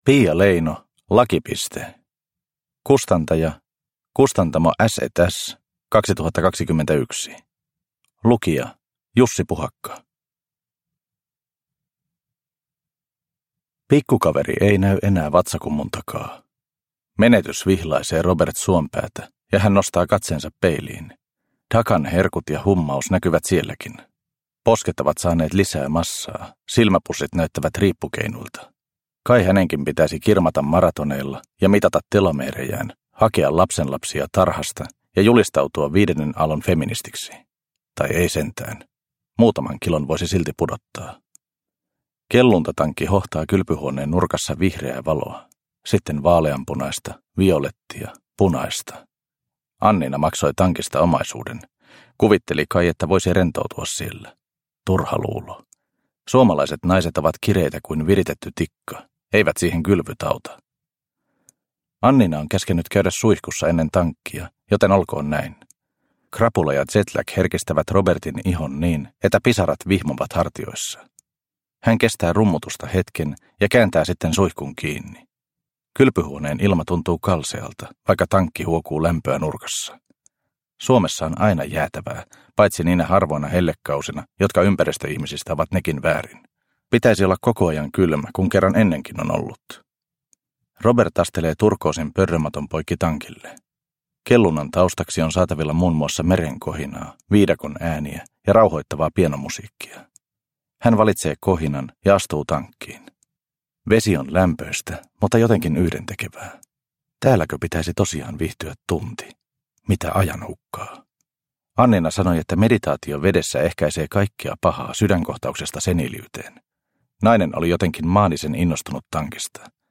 Lakipiste – Ljudbok – Laddas ner